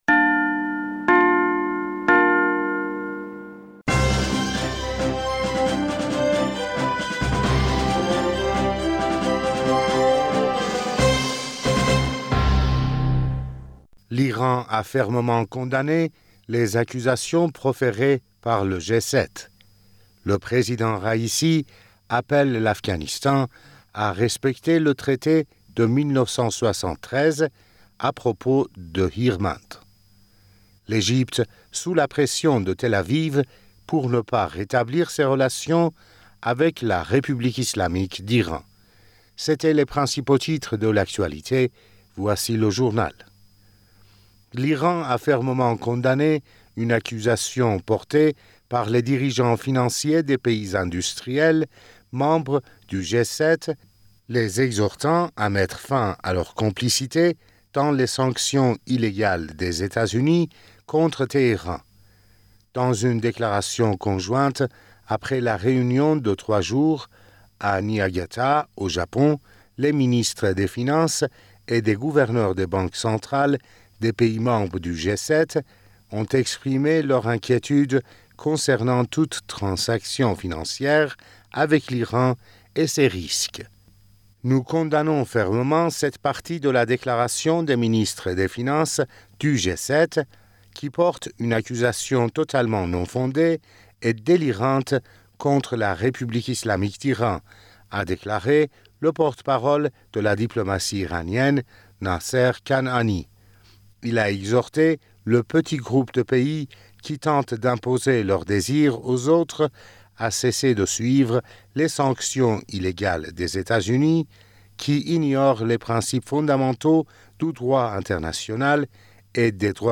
Bulletin d'information du 18 Mai 2023